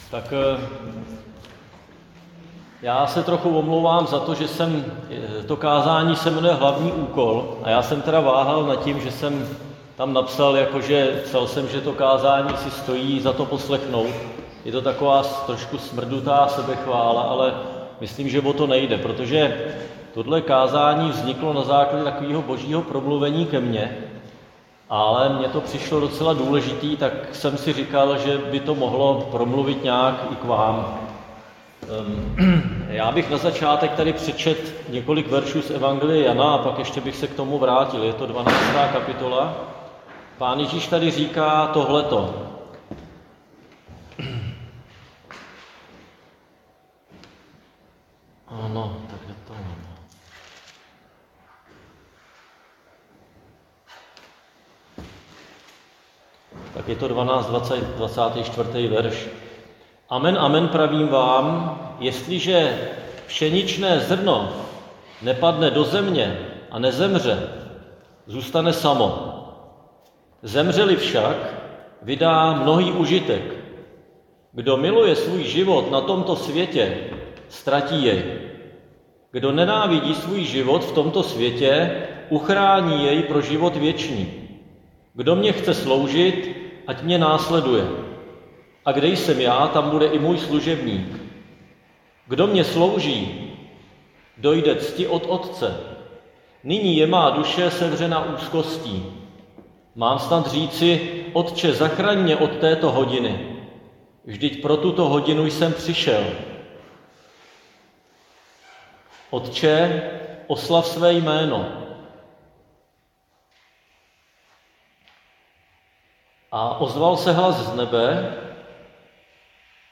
Křesťanské společenství Jičín - Kázání 9.2.2025